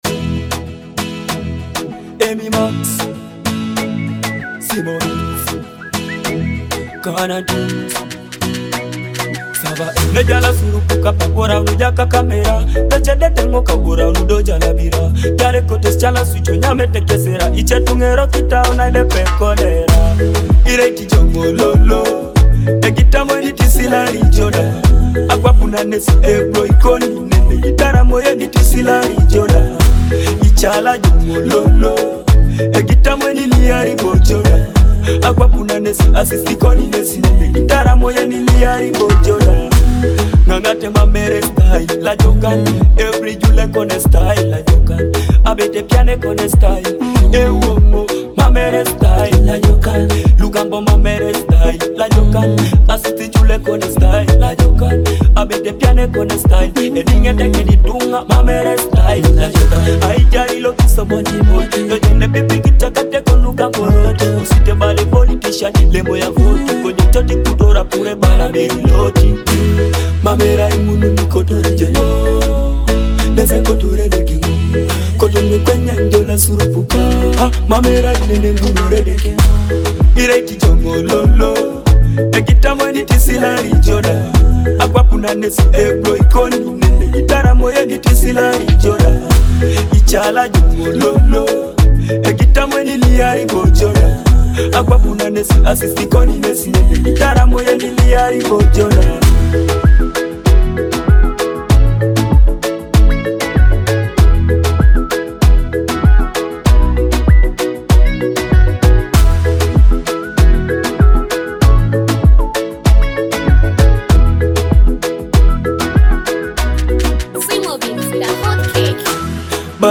With its high-energy rhythm and captivating vocals,